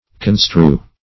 Construe \Con*strue\ (?; Archaic ?), v. t. [imp. & p. p.